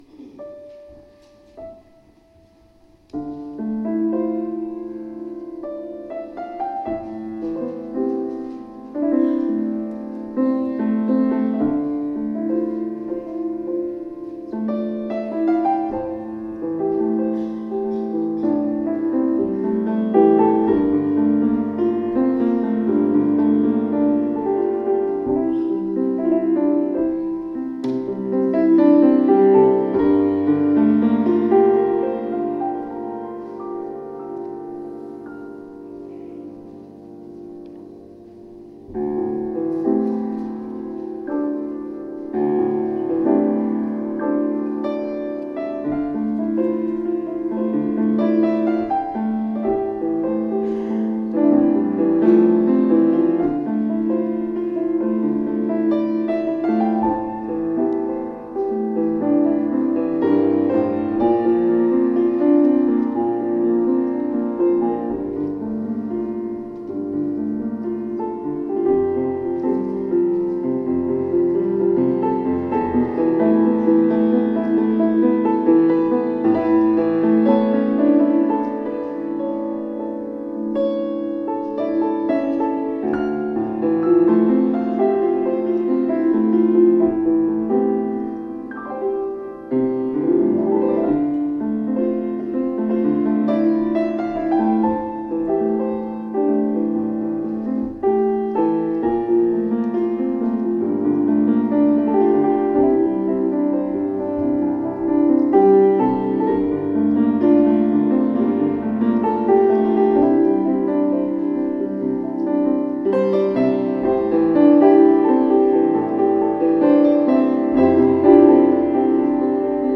from the Offertory in a 2014 service).